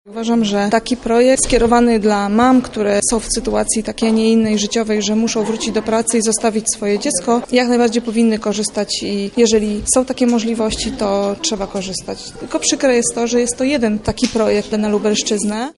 Realizację projektu ocenia jedna z matek